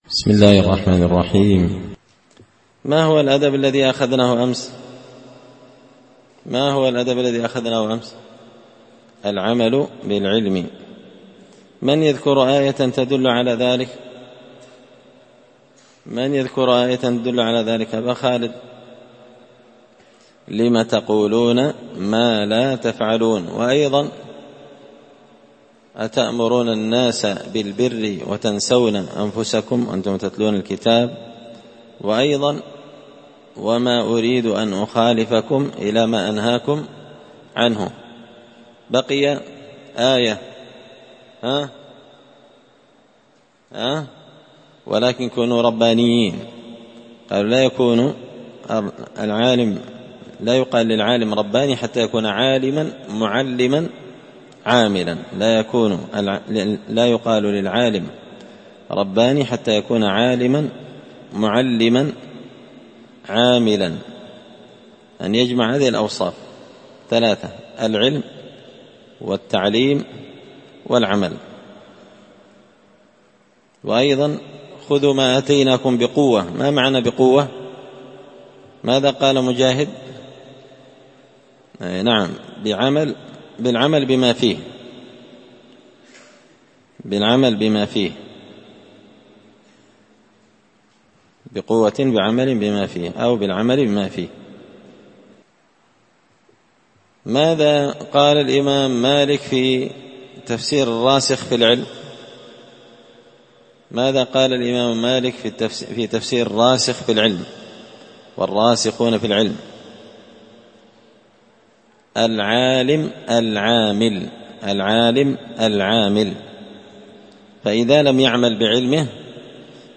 الدرس السادس عشر (16) تابع للأدب الحادي عشر العمل بالعلم